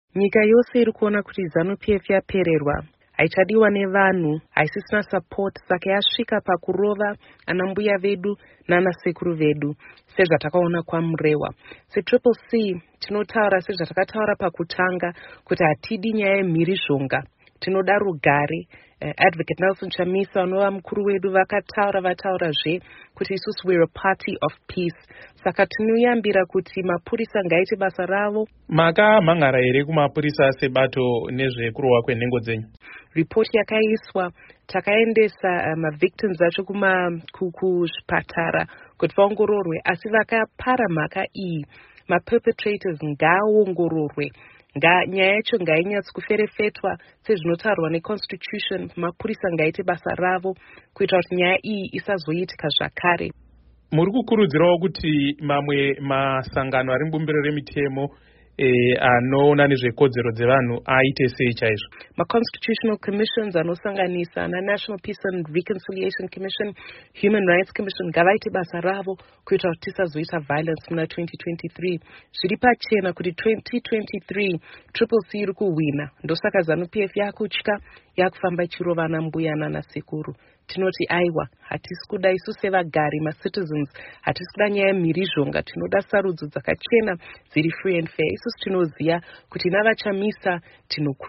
Hurukuro naMuzvare Fadzayi Mahere